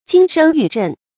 金声玉振 jīn shēng yù zhèn 成语解释 用钟发声；用磬收韵；集六音之大成。